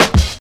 Index of /90_sSampleCDs/USB Soundscan vol.02 - Underground Hip Hop [AKAI] 1CD/Partition C/06-89MPC3KIT